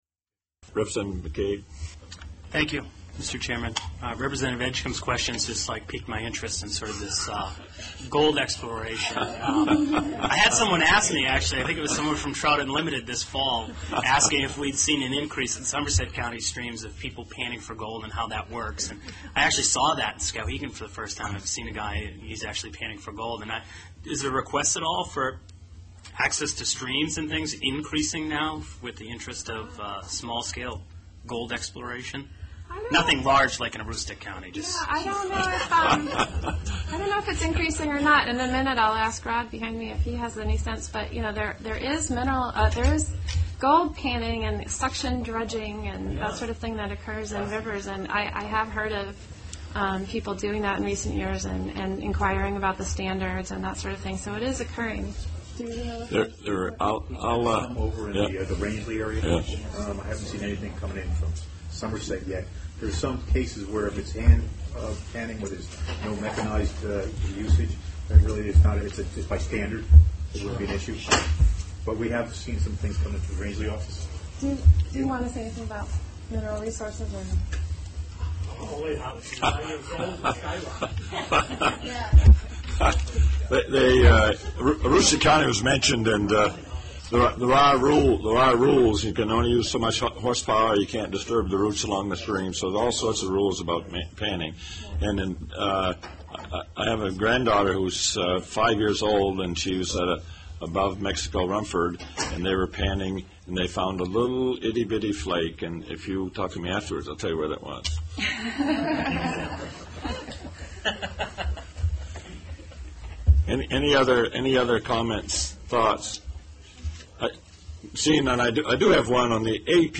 Recording of the committee starts about ten minutes into their worksession - sorry!